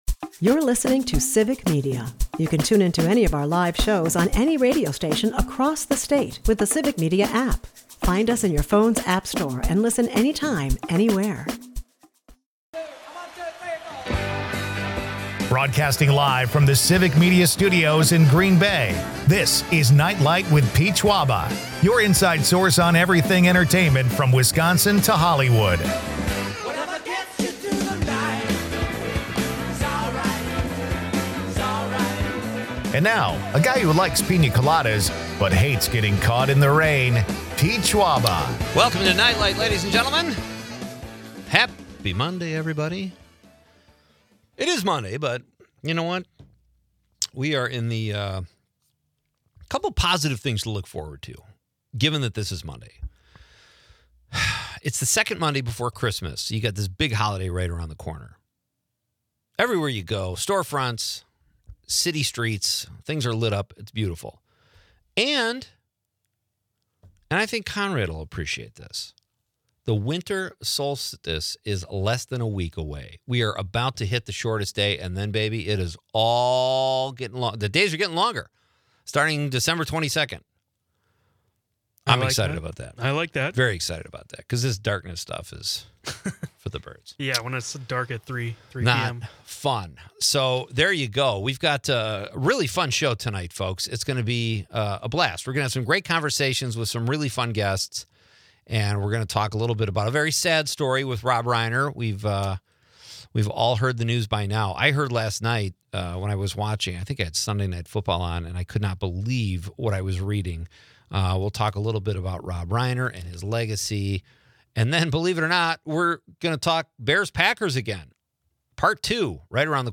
The show celebrates Reiner's diverse film legacy, from 'Stand By Me' to 'The Princess Bride.' Listeners weigh in on their favorite Reiner movies amidst tales of holiday decorations and winter weather woes.